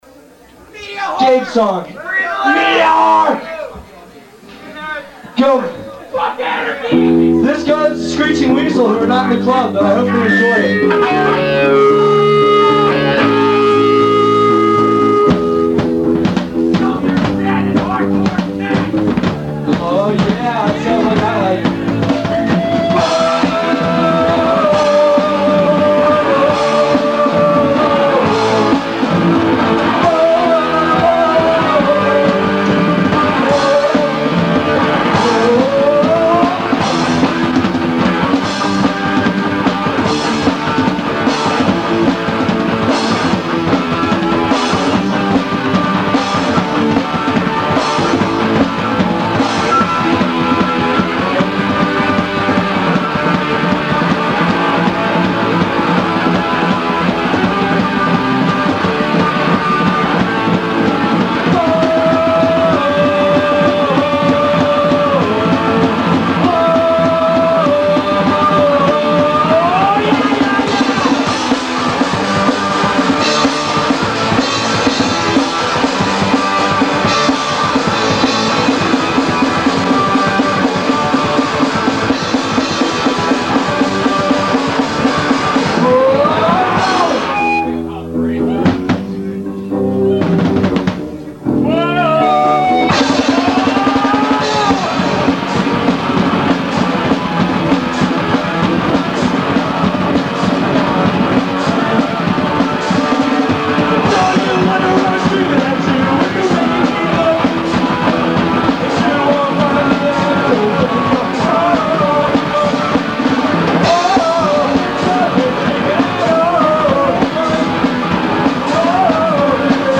side a – Blackhole, Philadelphia 1-05-92